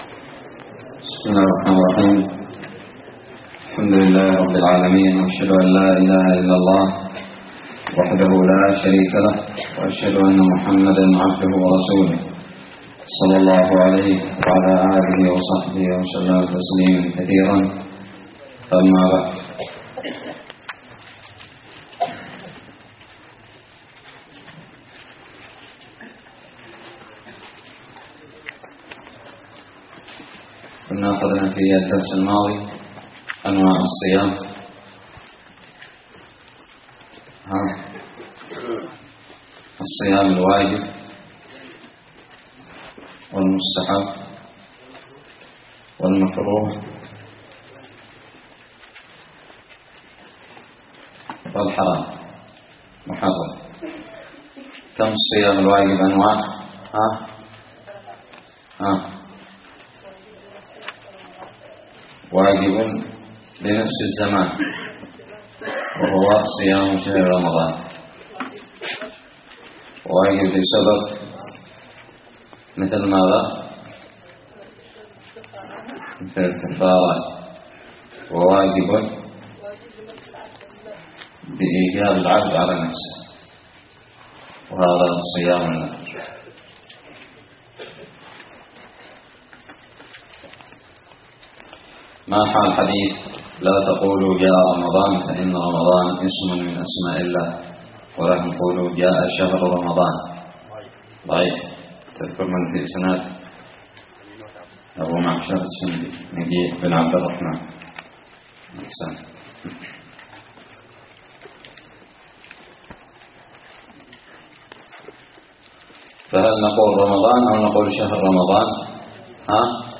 الدرس الثالث من كتاب الصيام من الدراري
ألقيت بدار الحديث السلفية للعلوم الشرعية بالضالع